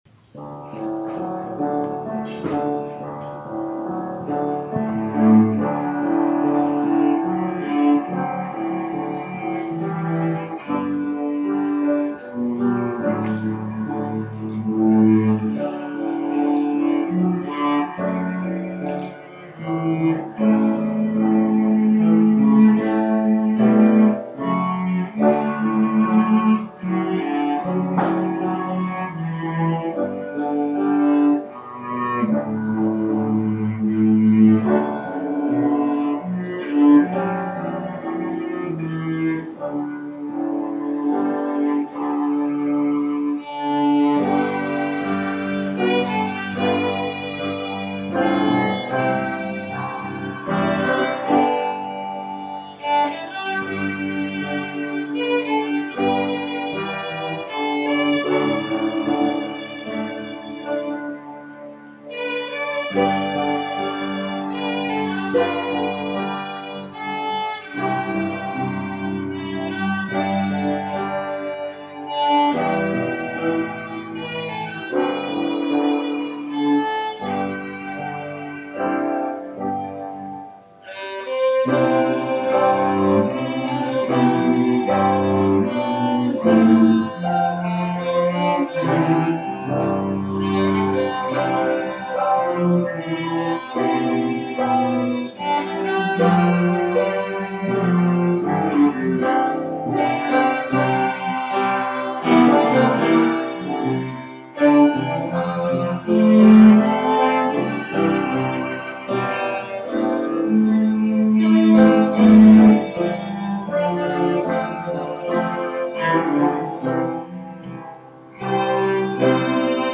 String Quartet - Amazing Grace